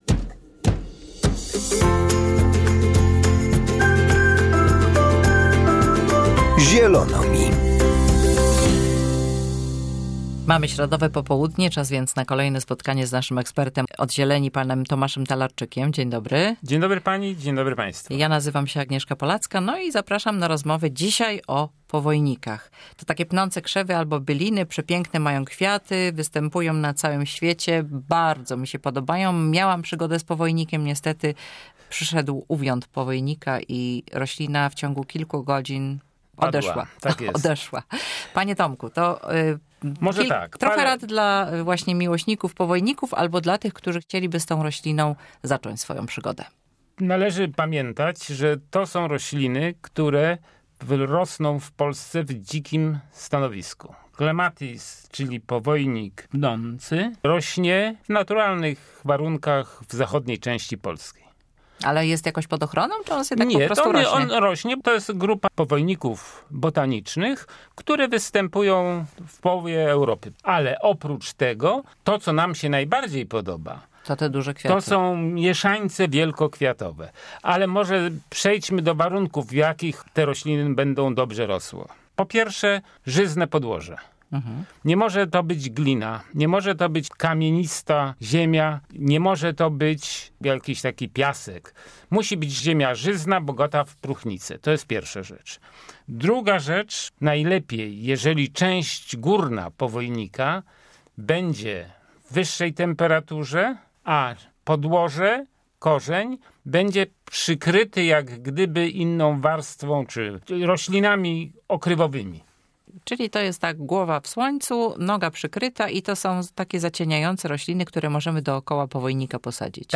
ROZMOWA O POWOJNIKACH